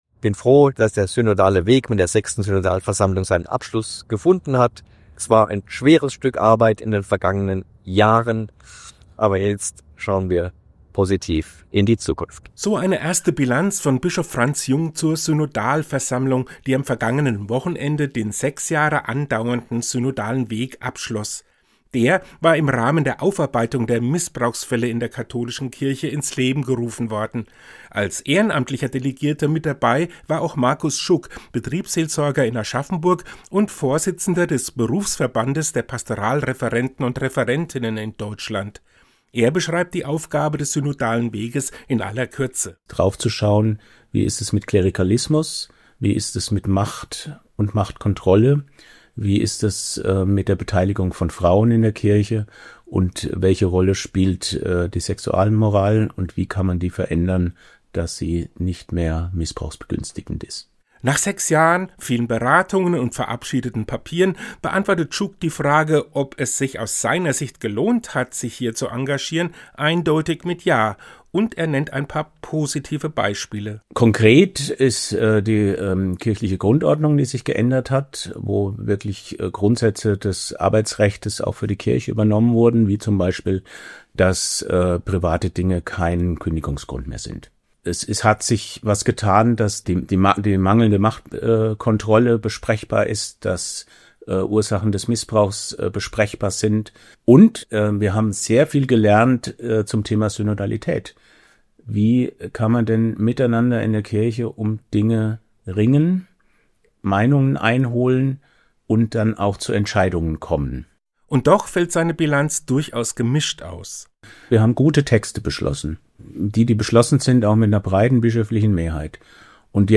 Den Radiopodcast finden Sie unten als Download!